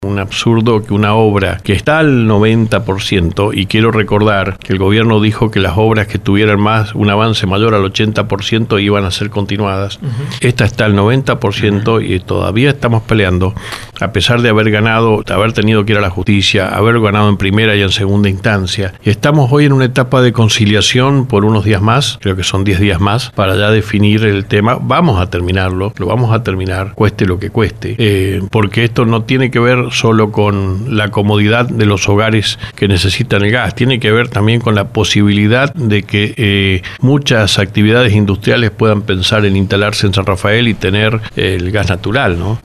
La falta de acompañamiento del Estado nacional, su economía, la autonomía municipal y la modernización del Estado fueron algunos de los temas sobre los que habló Omar Félix -intendente de San Rafael- en LV18, apenas unas horas más tarde de su discurso de apertura de sesiones ordinarias del Concejo Deliberante.